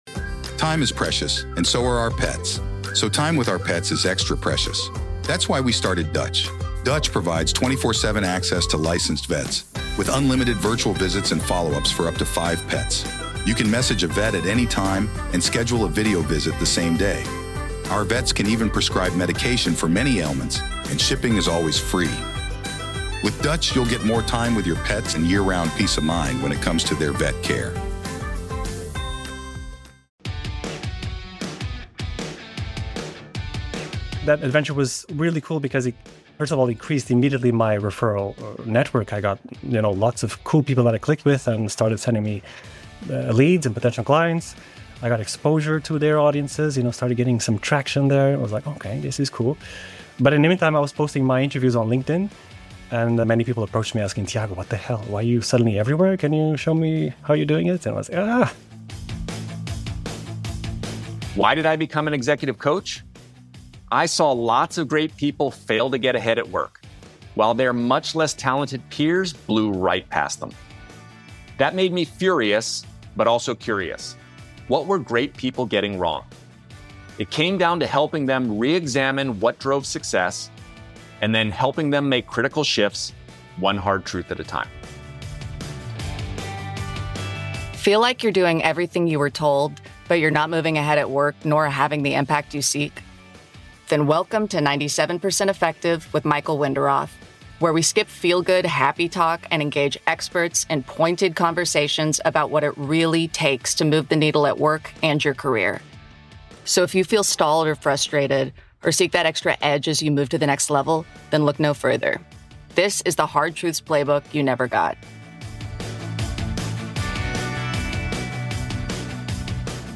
Season #1 focuses on Power and Influence, two widely acknowledged (but poorly understood) forces that can help you rise, lead more effectively and get big things done. Each week we have candid conversations with an academic, coach, or executive, helping you gain new insights to better navigate your work and career.